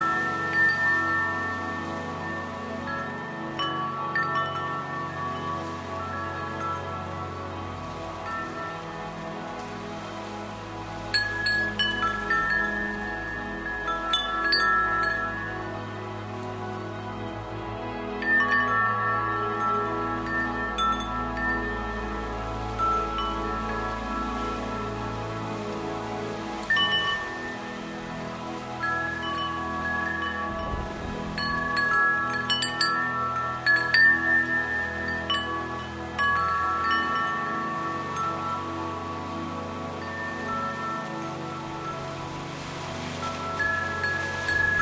AmLife’s Original Frequency + Nature’s Frequency + Spiritual Healing Music
悠悠风铃 WIND CHIME STAY 396Hz
平静心情 Calmness
安宁祥和 Peaceful and serene
wind.mp3